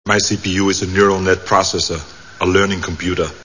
Terminator 2 Movie Sound Bites